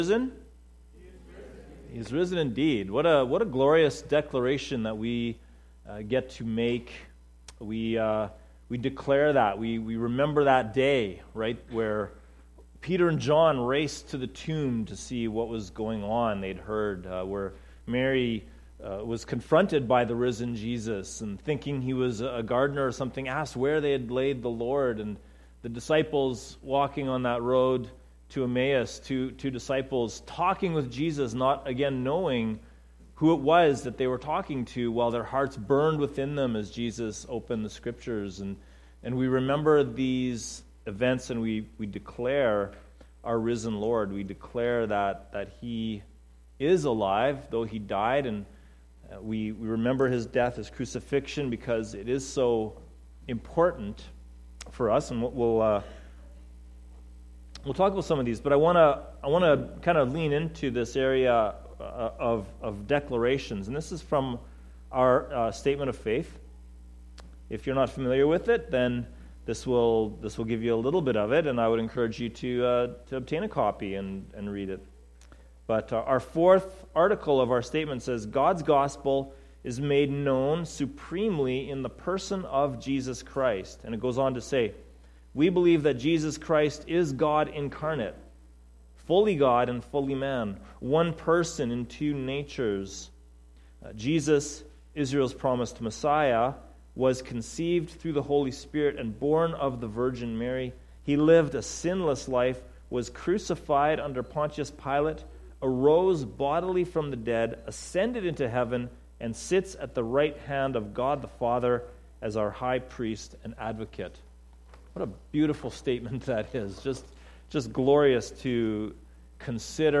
Easter Sunday Service